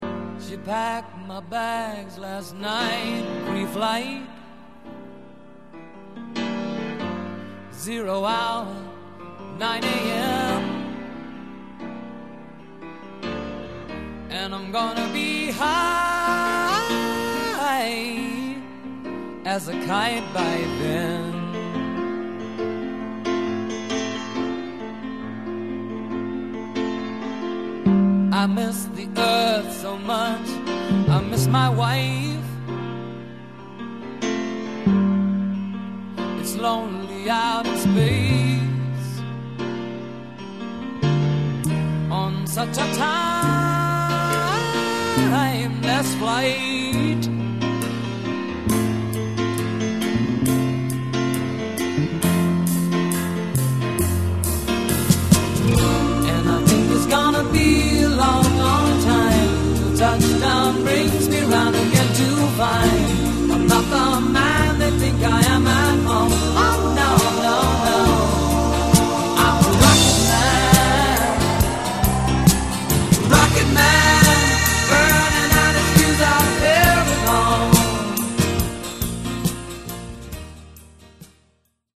le venature Rock\Blues
Nella voce, nel pianoforte e nella composizione.